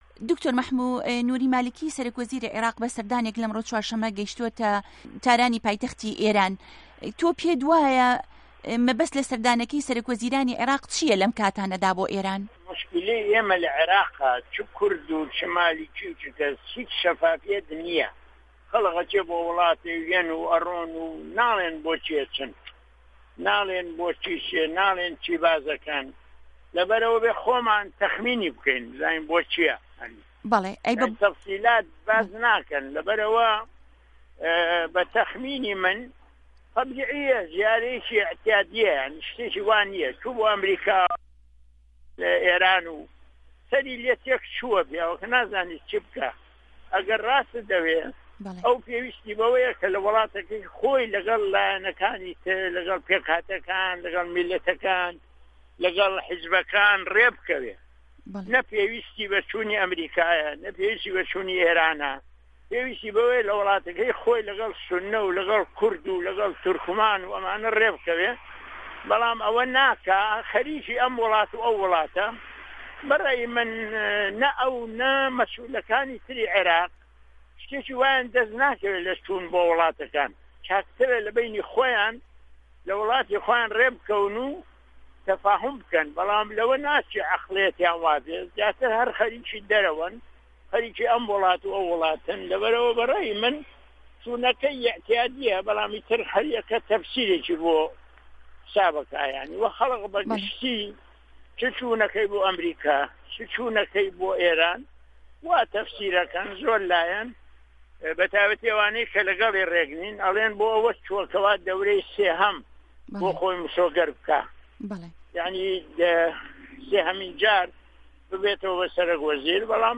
به‌ڵام د. مه‌حموود عوسمان ئه‌ندامی په‌‌رله‌مانی عێراق له‌ میانه‌ی گفتوگۆیه‌کدا له‌گه‌ڵ به‌شی کوردی ده‌نگی ئه‌مه‌ریکا گوتی به‌ بۆچوونی ئه‌و گه‌شته‌که‌ی مالیکی بۆ ئێران ئاساییه‌ وه‌ شتێکی وای لێده‌ست ناکه‌وێت.
گفتوگۆ ڵه‌گه‌ڵ د. مه‌حموود عوسمان 4ی 12ی ساڵی 2013